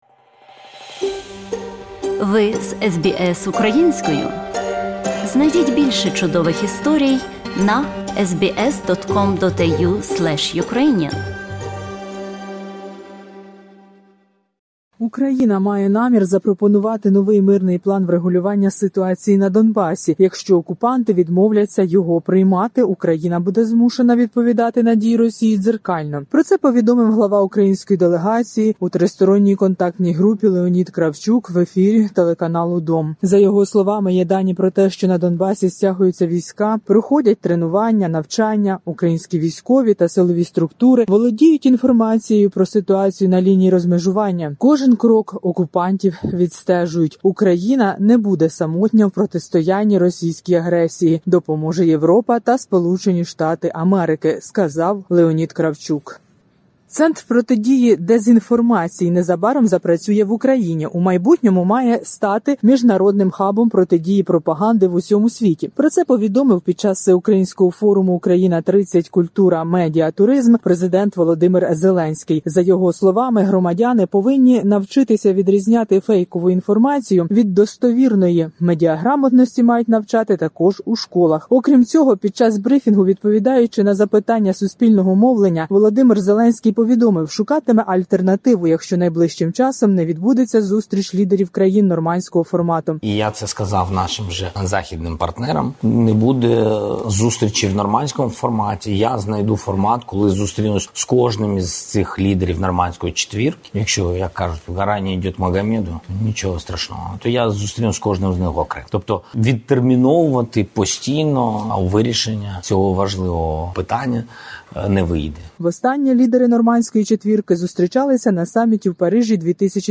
Новин з України за 11 березня 2021 року